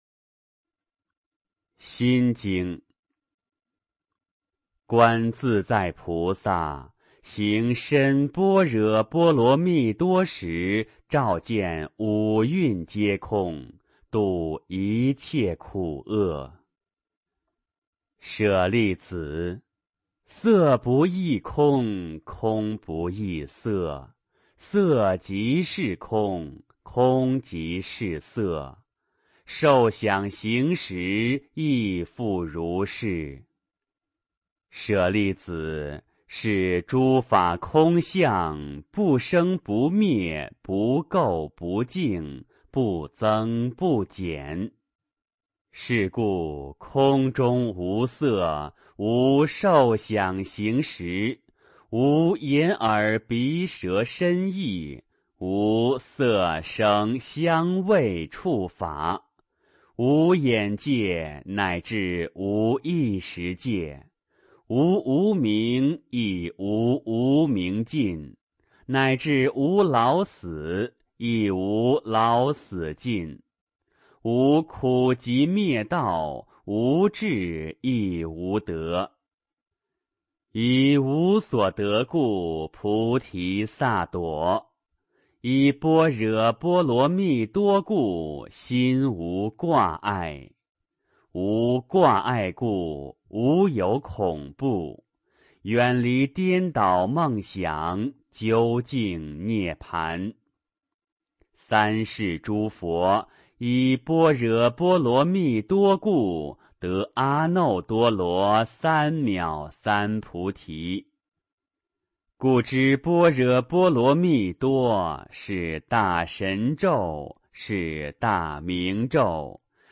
心经.读诵
诵经